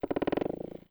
CREAK_Subtle_01_mono.wav